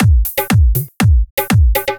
120 BPM Beat Loops Download